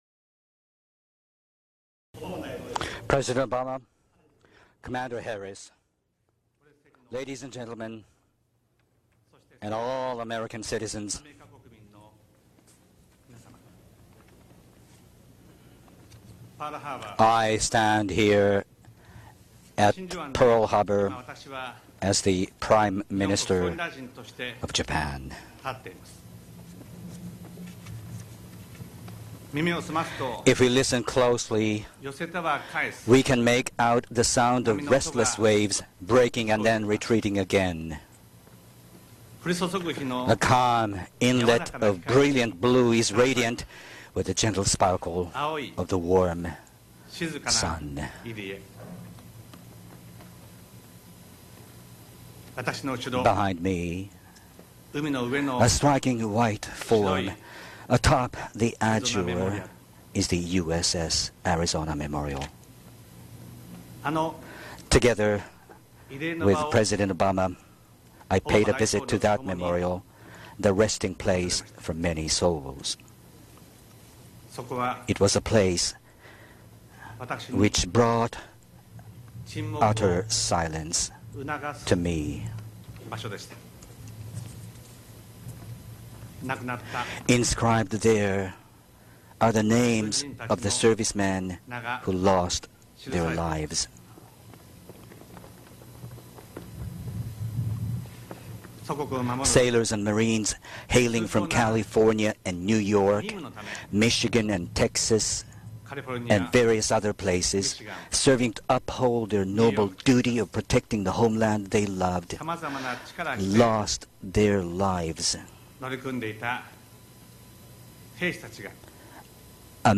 Remarks by U.S. President Barack Obama and Japanese Prime Minister Shinzō Abe at Pearl Harbor